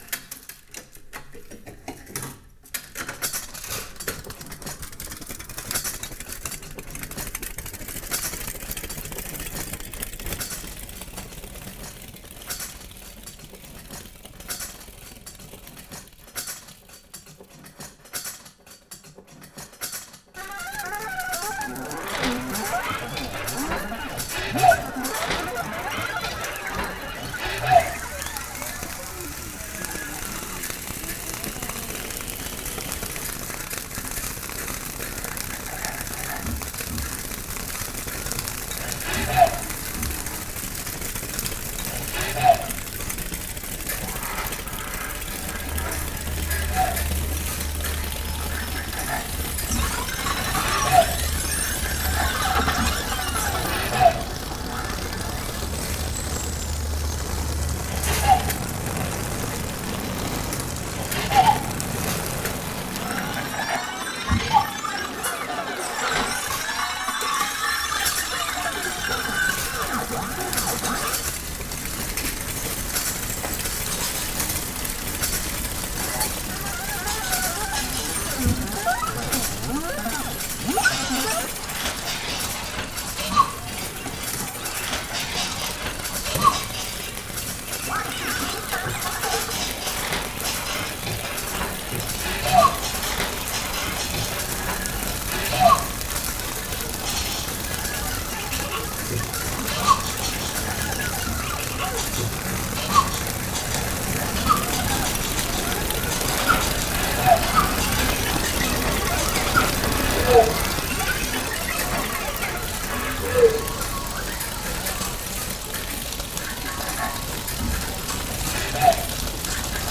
Produced at the Métamorphoses d’Orphée Studio in Ohain